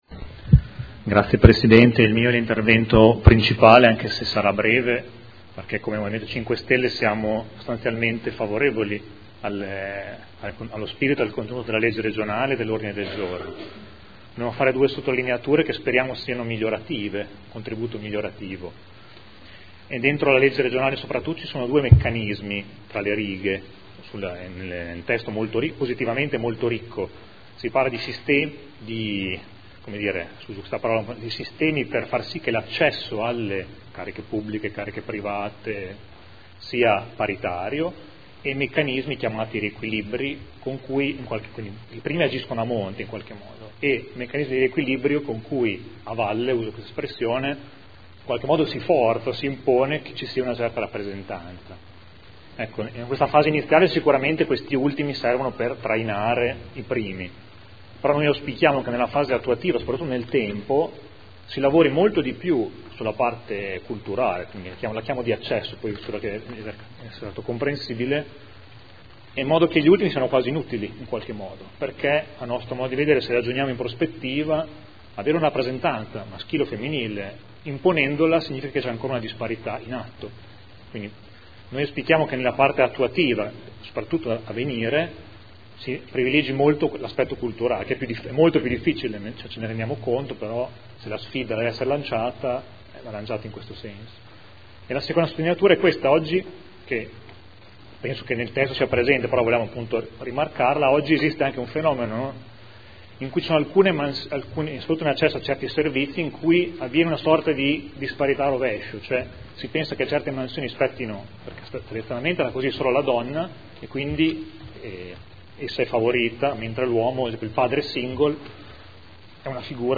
Mario Bussetti — Sito Audio Consiglio Comunale